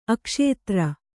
♪ akṣētra